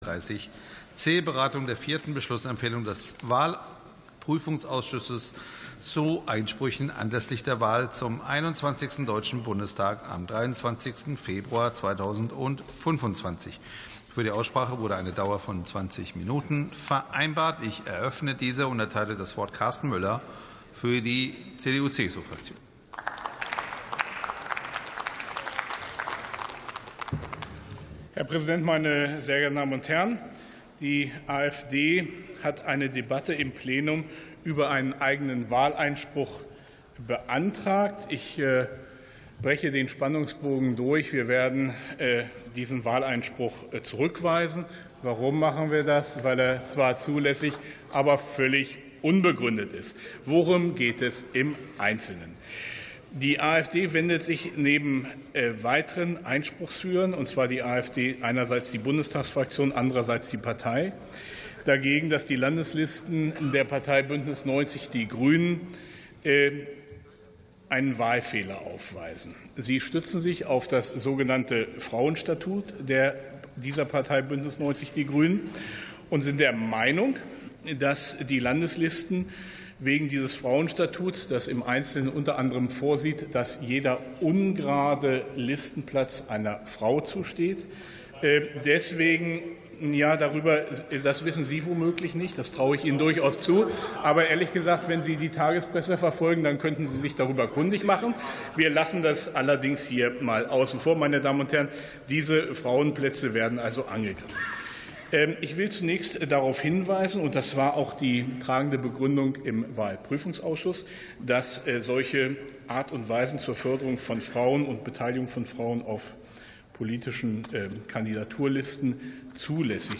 59. Sitzung vom 26.02.2026. TOP 32 c: Wahleinsprüche anlässlich der Bundestagswahl 2025 ~ Plenarsitzungen - Audio Podcasts Podcast